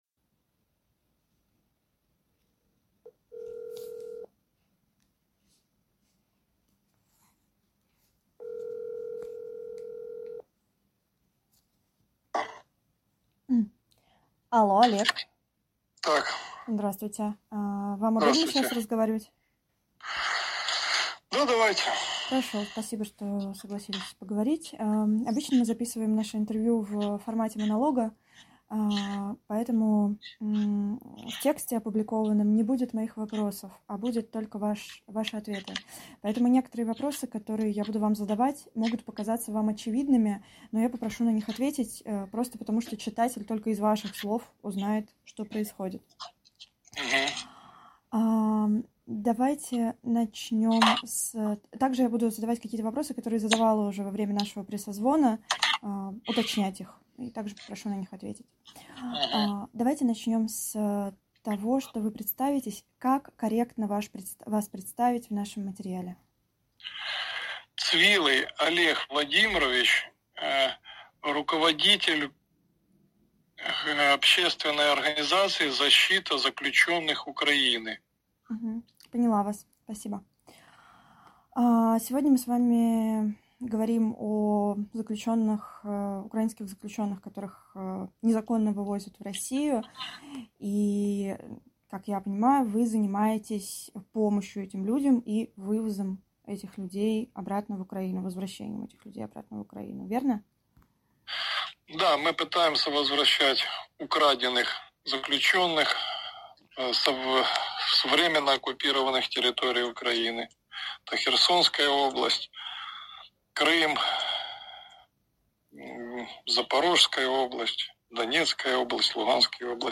Россия вывозит с оккупированных территорий заключенных и делает их рабами — личные свидетельства войны в Украине, архив «Службы поддержки»